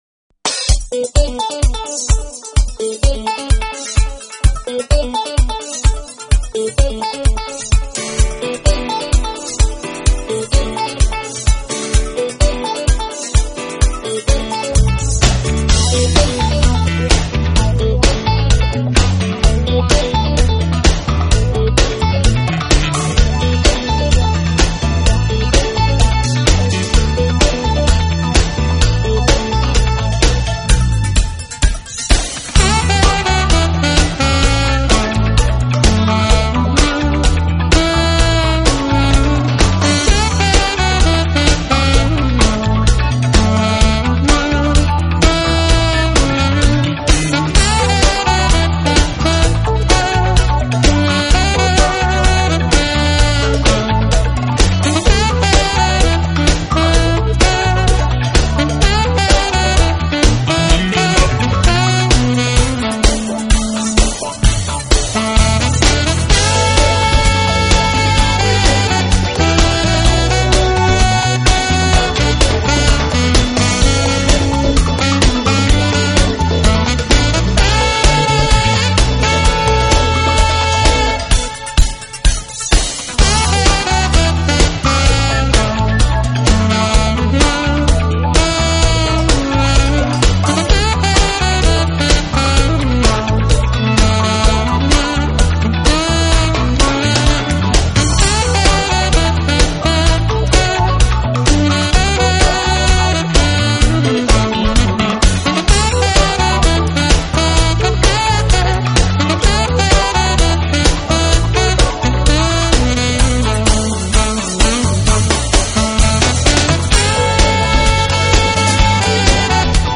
Genre: Smooth Jazz
Instrumental pop/contemporary jazz
saxophone